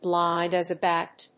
アクセント(as) blínd as a bát [móle]